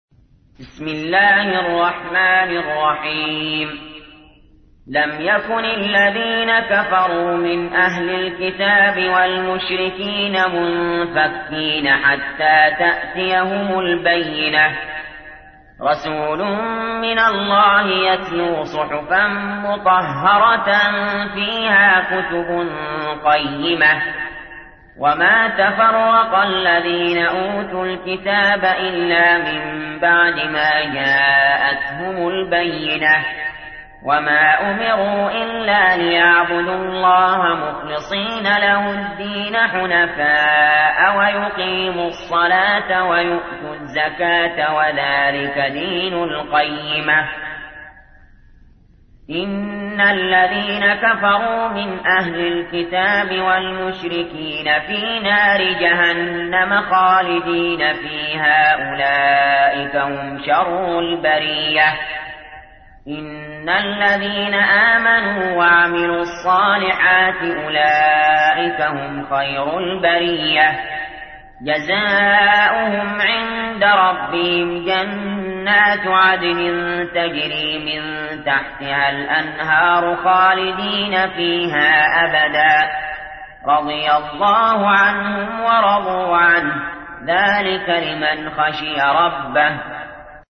تحميل : 98. سورة البينة / القارئ علي جابر / القرآن الكريم / موقع يا حسين